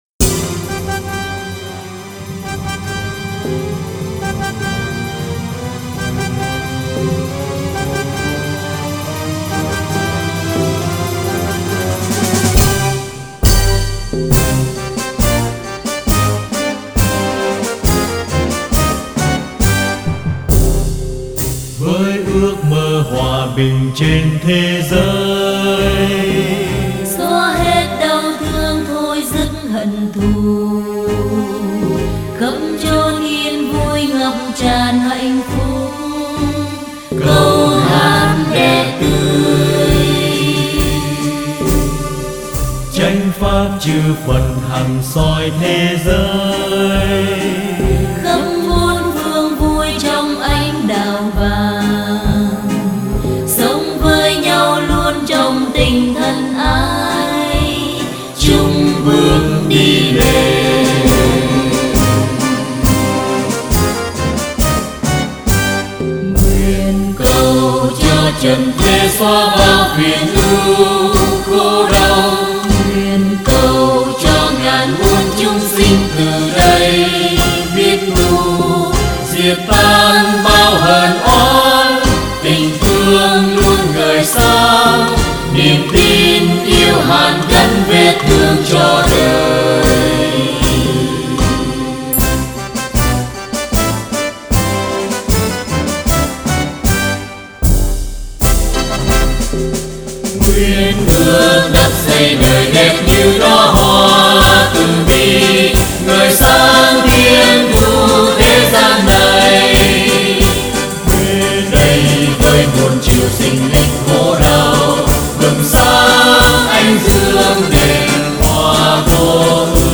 hợp xướng